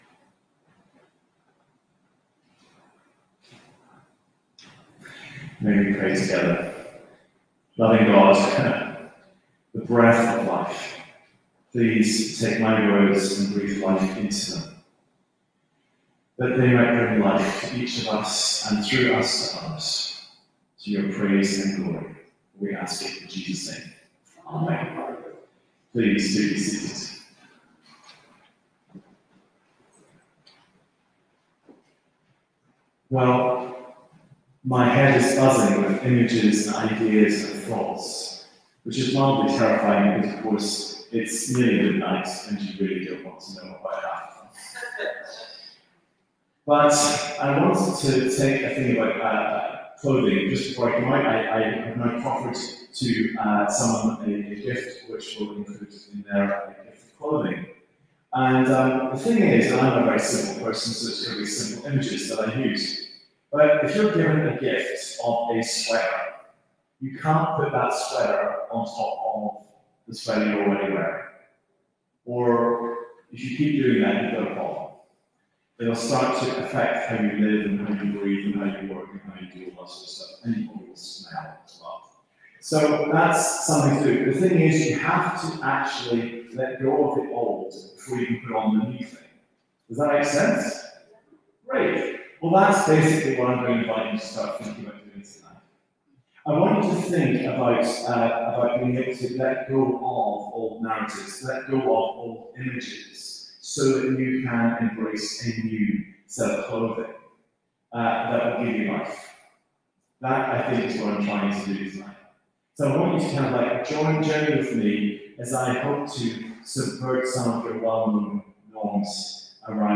Sermons | St. Paul's Anglican Church